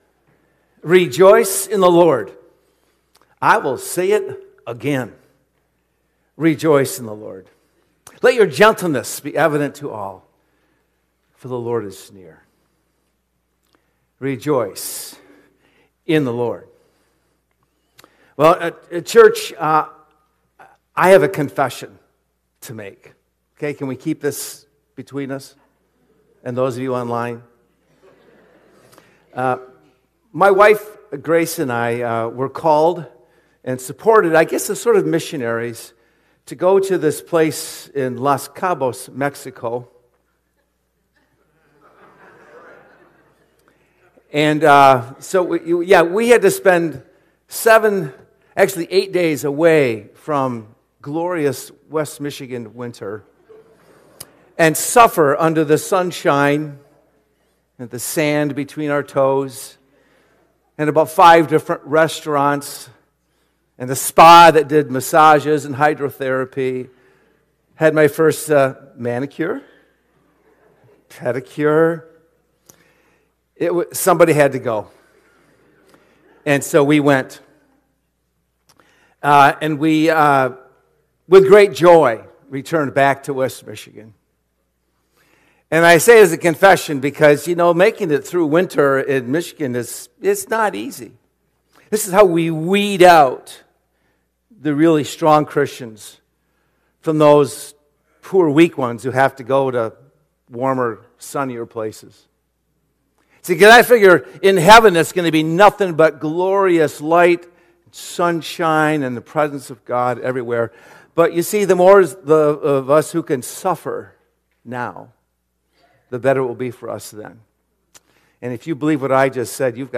Sermon Recordings | Faith Community Christian Reformed Church
“Keep on Rejoicing” February 8 2026 A.M. Service